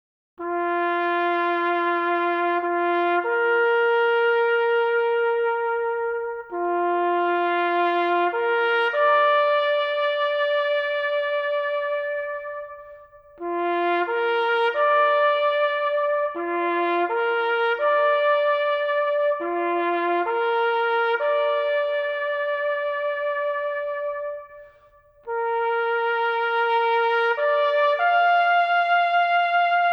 Genre: Singer/Songwriter